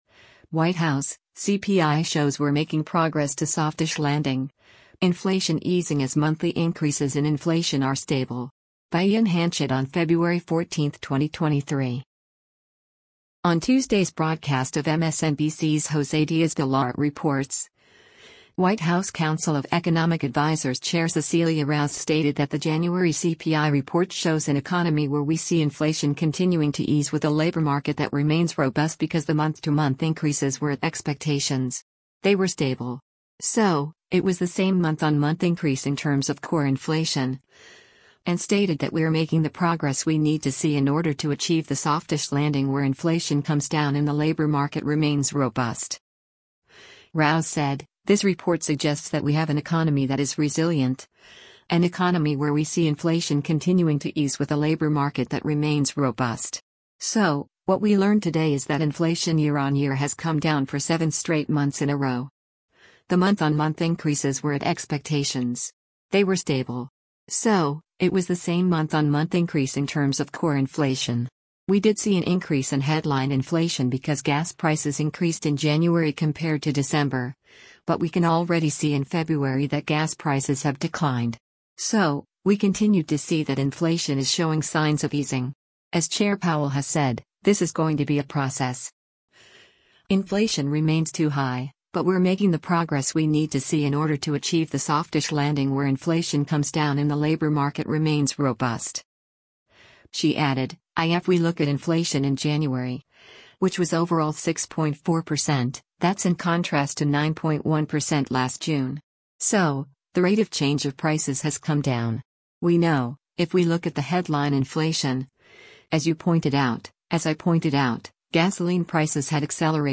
On Tuesday’s broadcast of MSNBC’s “Jose Diaz-Balart Reports,” White House Council of Economic Advisers Chair Cecilia Rouse stated that the January CPI report shows “an economy where we see inflation continuing to ease with a labor market that remains robust” because the month-to-month increases “were at expectations.